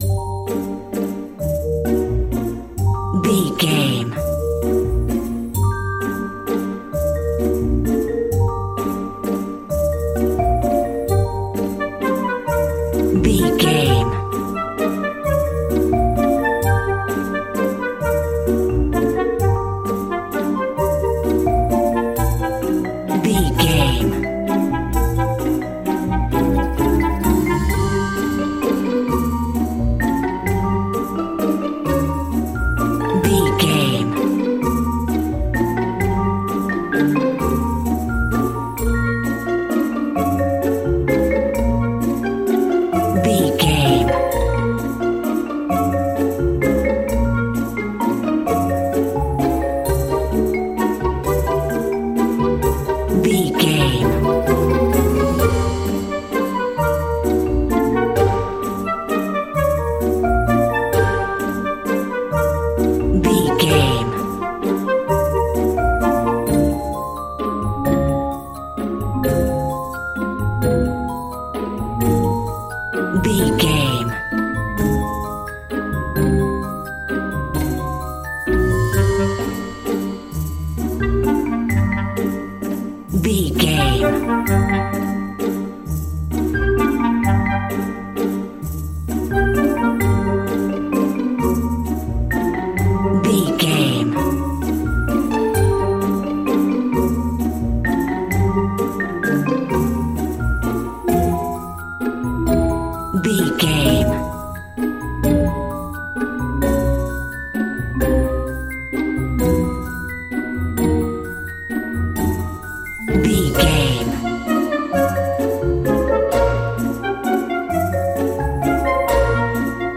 Uplifting
Ionian/Major
D
Slow
flute
oboe
strings
orchestra
cello
double bass
percussion
violin
sleigh bells
silly
goofy
comical
cheerful
perky
Light hearted
quirky